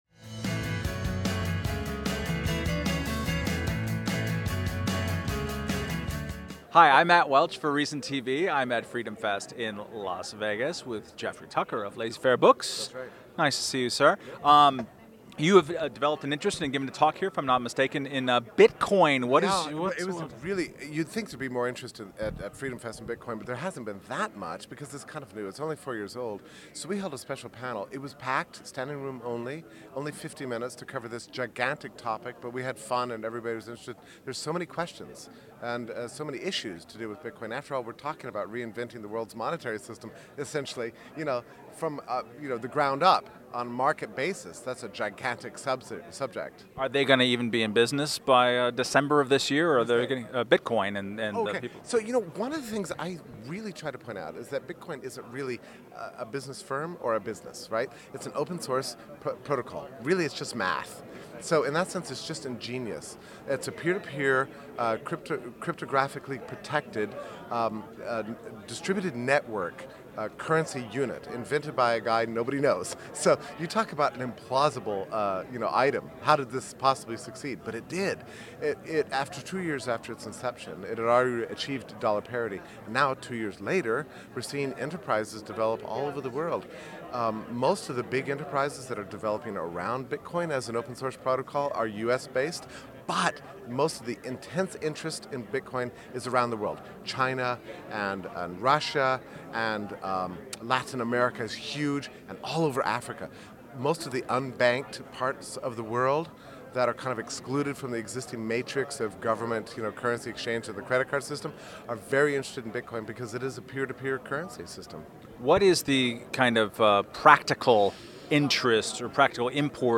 Held each July in Las Vegas, Freedom Fest is attended by around 2,000 limited-government enthusiasts and libertarians. ReasonTV spoke with over two dozen speakers and attendees and will be releasing interviews over the coming weeks.